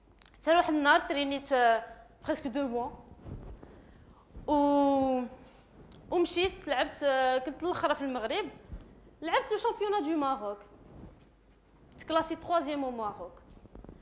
TEDx_code_switching.mp3